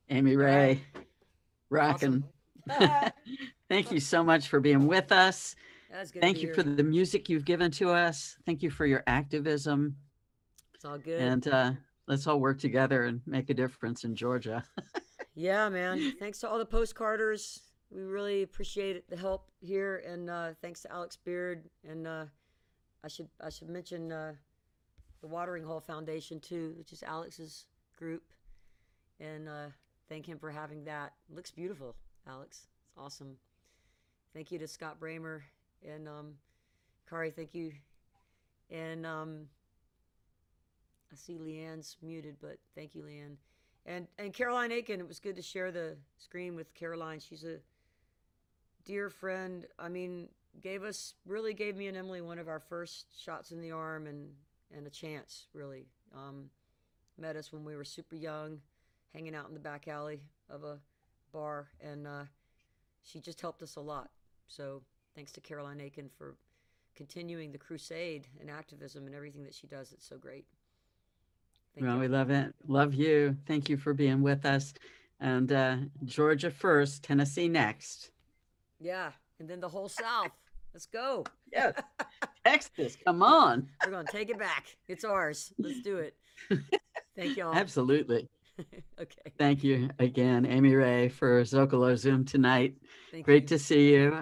(audio from a portion of the program captured from webcast)
16. conversation with amy ray (1:34)